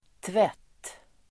Uttal: [tvet:]